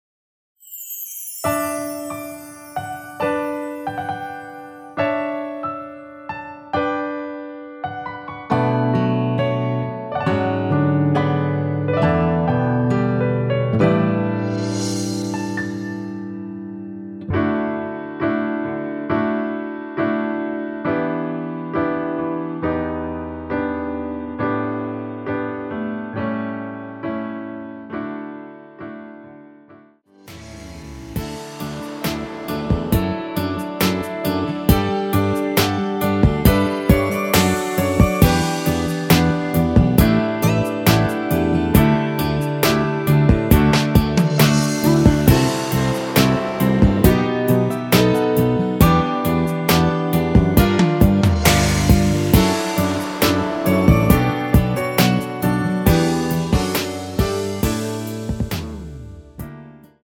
대부분의 여성분이 부르실수 있는 키로 제작 하였습니다.
앞부분30초, 뒷부분30초씩 편집해서 올려 드리고 있습니다.
중간에 음이 끈어지고 다시 나오는 이유는